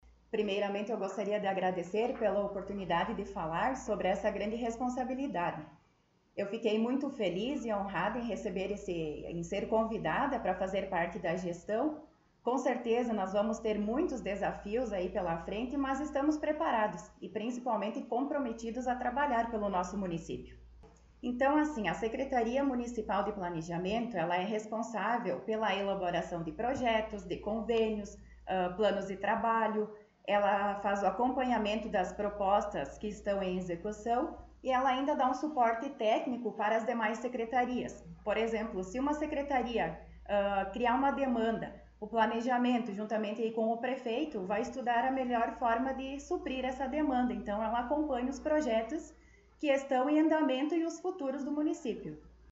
Secretária Municipal de Planejamento concedeu entrevista